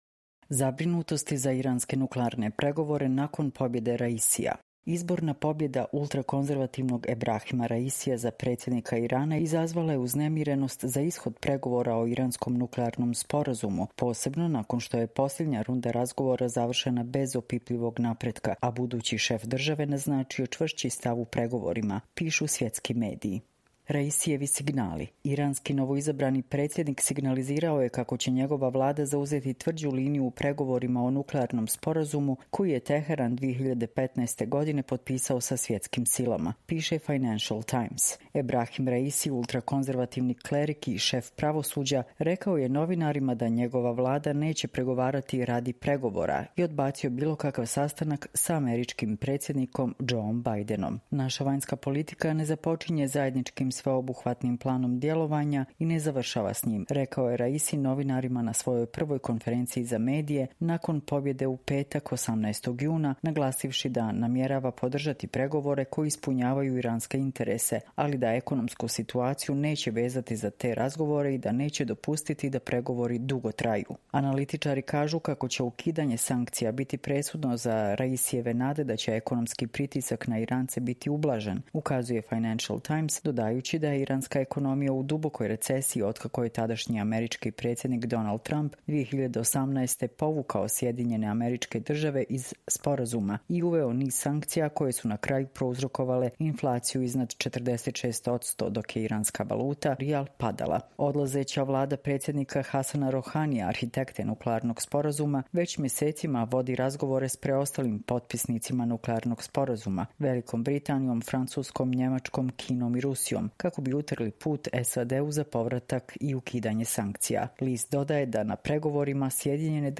Čitamo vam: Zabrinutosti za iranske nuklearne pregovore nakon pobjede Raisija